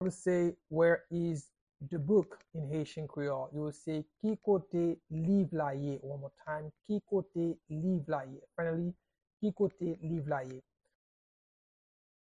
Pronunciation and Transcript:
How-to-say-Where-is-the-book-in-Haitian-Creole-–-Ki-kote-liv-la-ye-pronunciation-by-a-Haitian-teacher.mp3